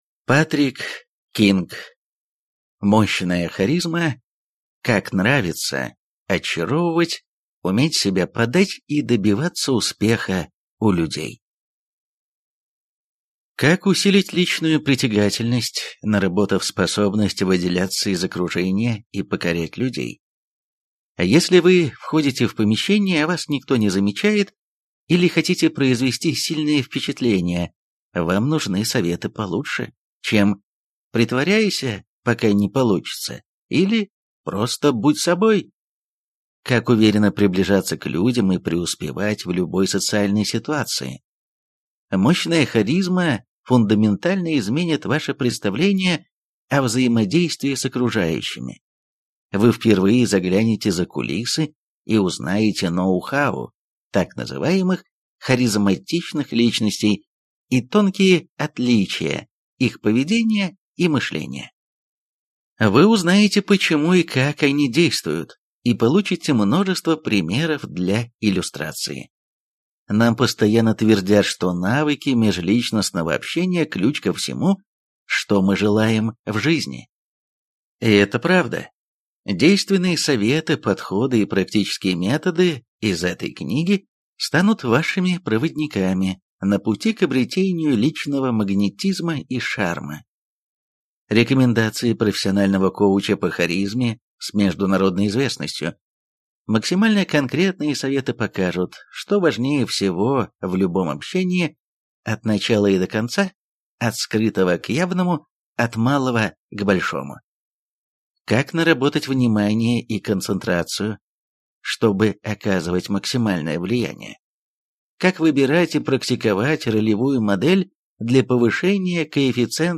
Аудиокнига Мощная харизма. Как нравиться, очаровывать, уметь себя подать и добиваться успеха у людей | Библиотека аудиокниг